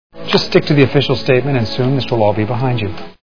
Iron Man Movie Sound Bites